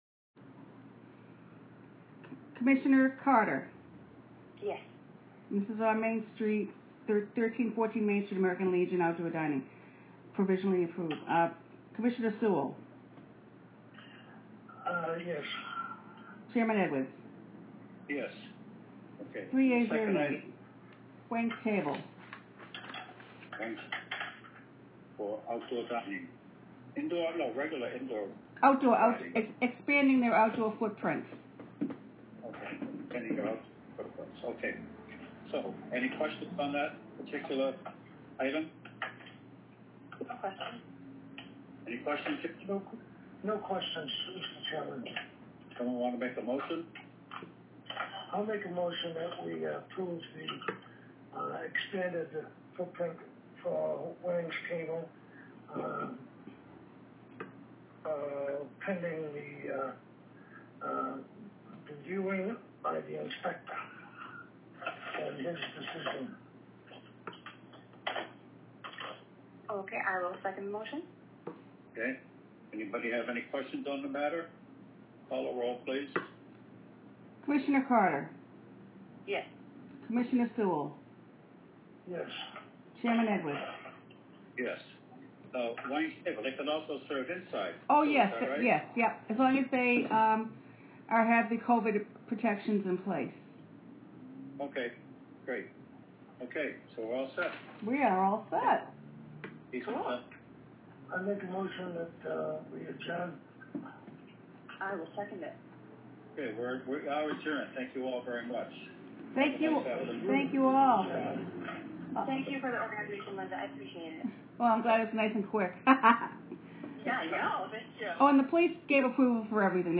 June 23 2020 remote special -